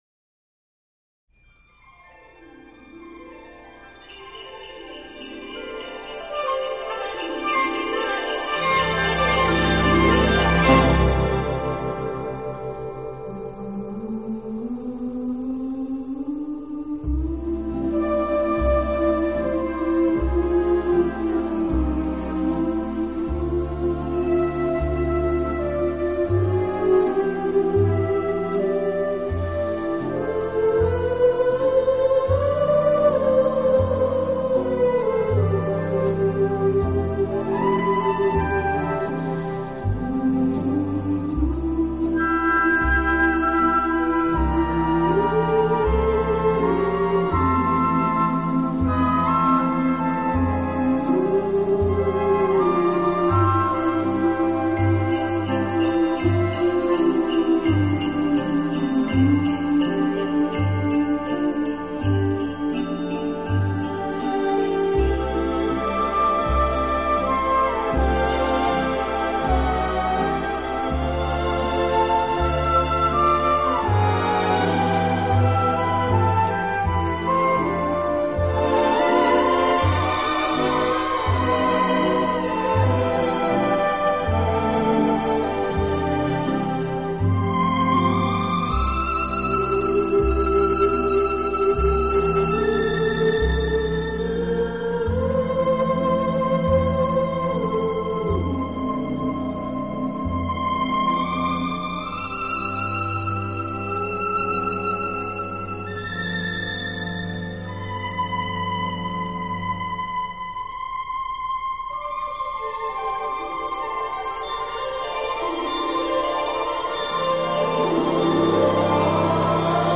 轻音乐专辑